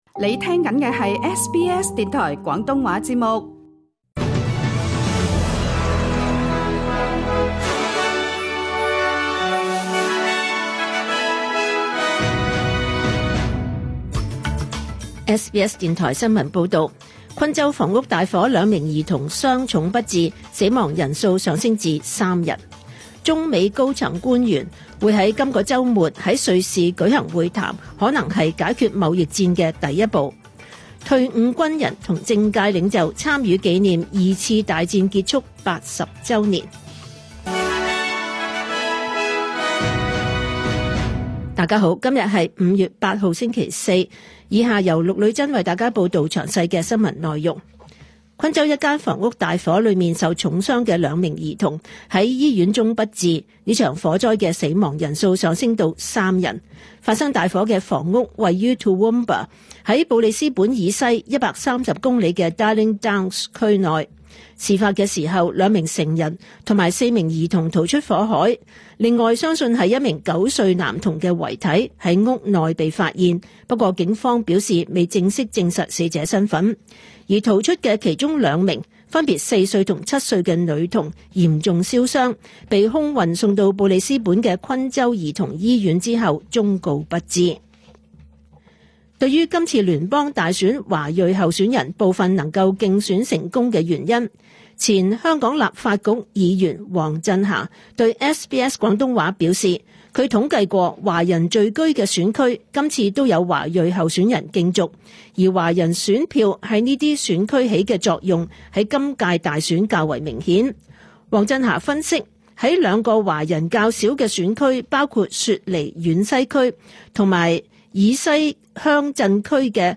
2025 年 5 月 8 日 SBS 廣東話節目詳盡早晨新聞報道。